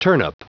Prononciation du mot turnip en anglais (fichier audio)
Prononciation du mot : turnip